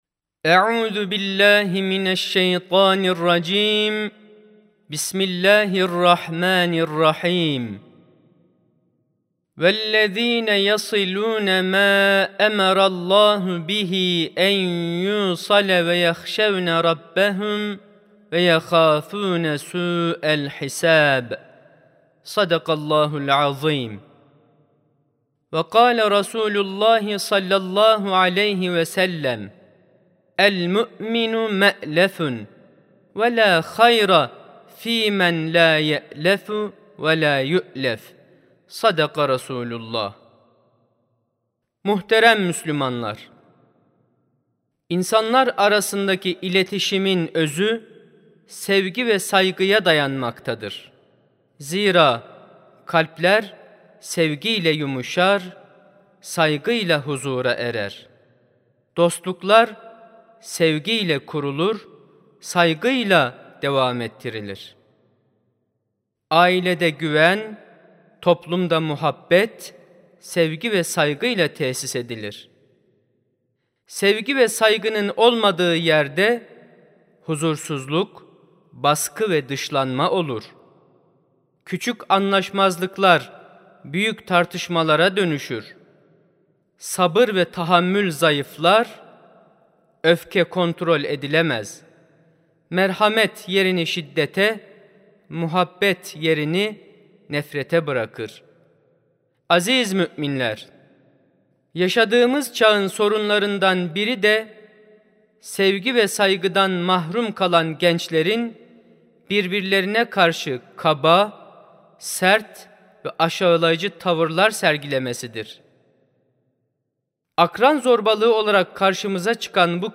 23 Ocak 2026 Tarihli Cuma Hutbesi
Sesli Hutbe (Akran İlişkileri).mp3